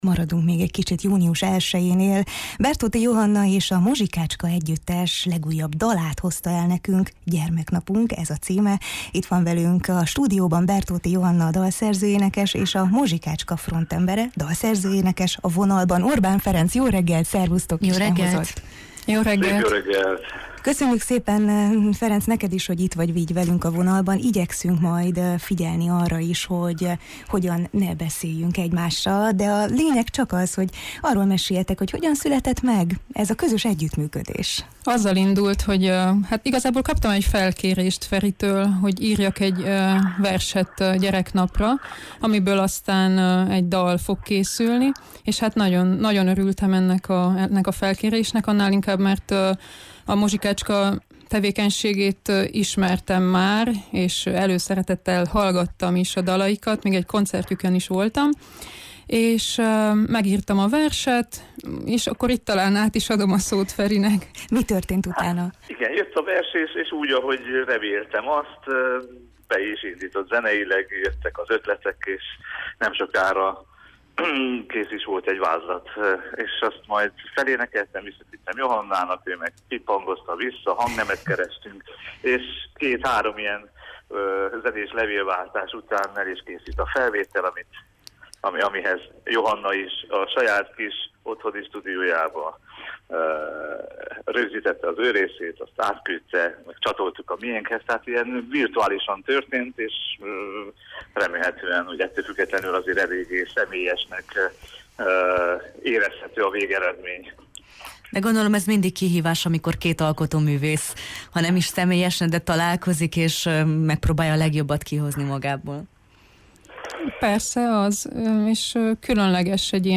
A Jó reggelt, Erdély!-ben beszélgettünk az alkotókkal a közös munkáról, gyermeklelkületről: